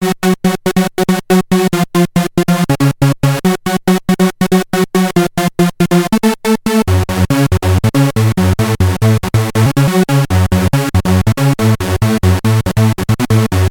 But there are additional measures for ultimative fatness: the usage of detuned LFOs to modulate the pulsewidth.
• we set the Inversion flag In2 for these MODs, so that the right audio channel will be modulated inverse to the left channel - this guarantees an even more fat sound! :-)